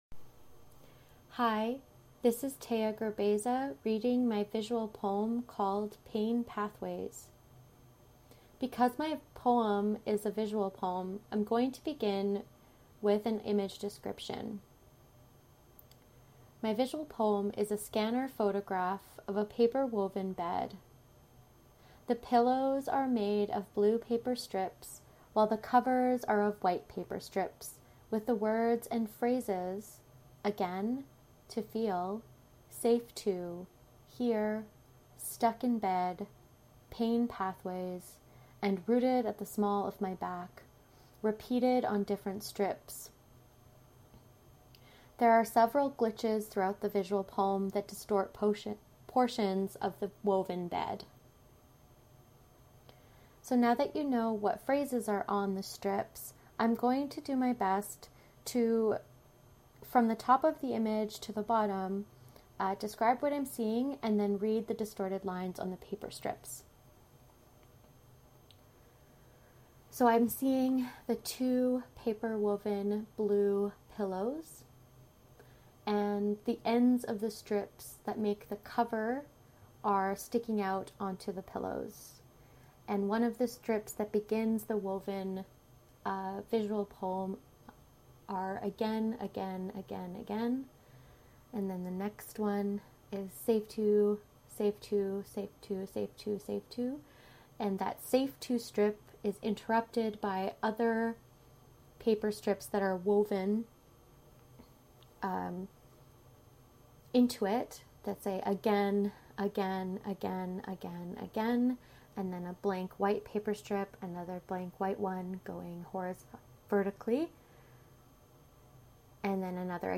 (listen to the poem, read by the artist/author)